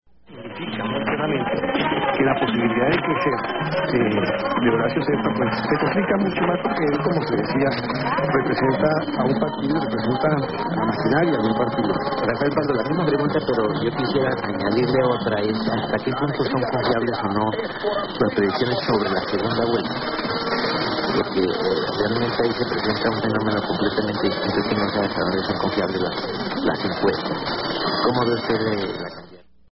Seeming anmt about Antena Popular on background station